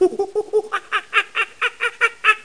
00587_Sound_singe.mp3